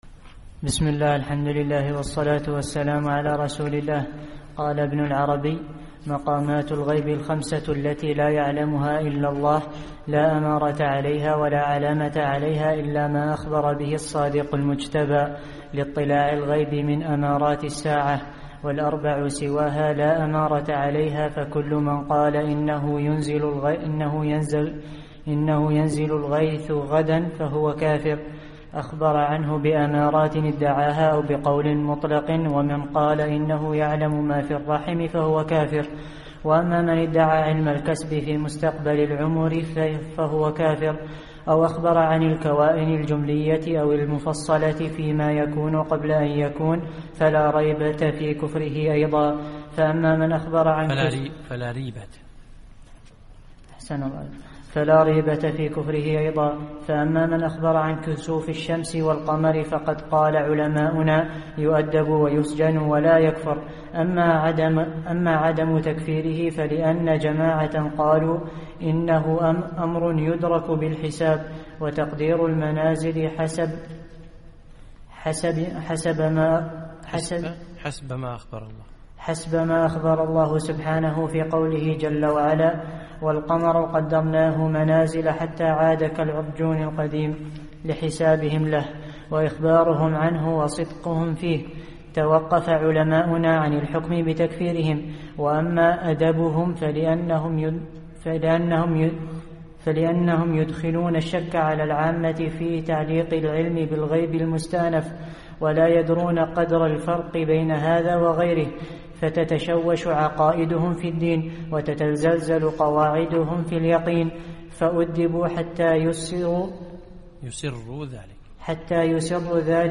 الدرس الثالث عشر